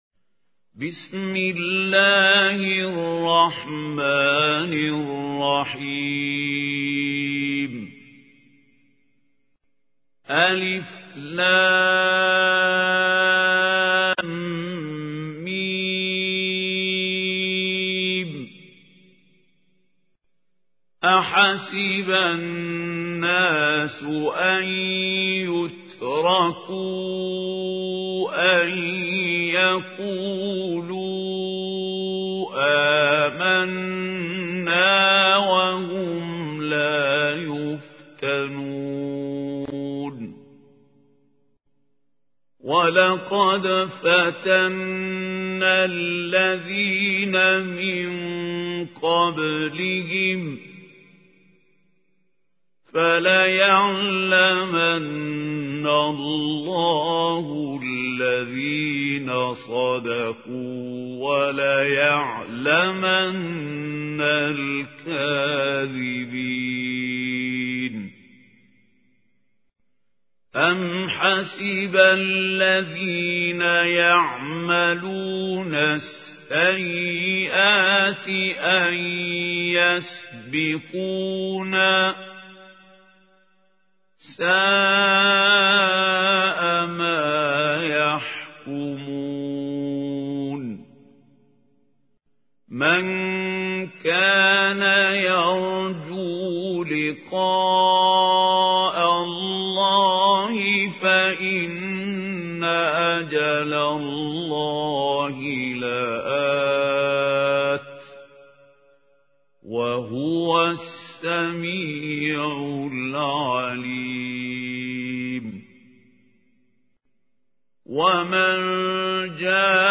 القارئ: الشيخ خليل الحصري